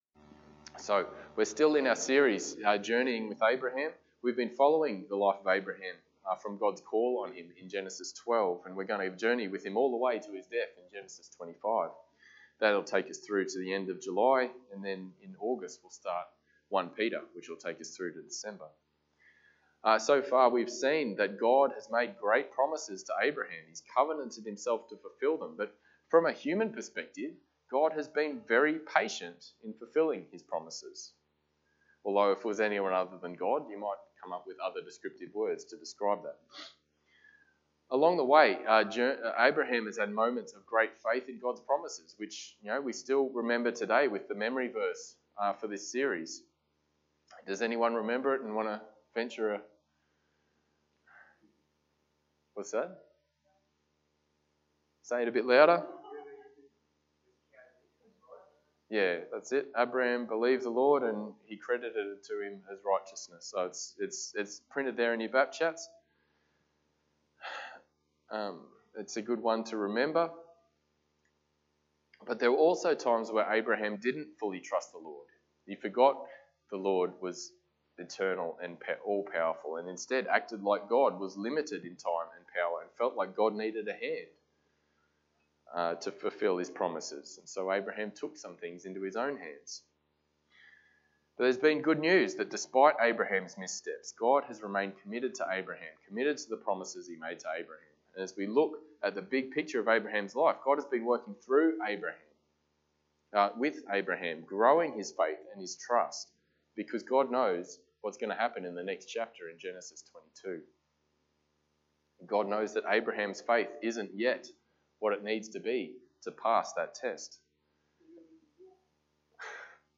Passage: Genesis 21 Service Type: Sunday Morning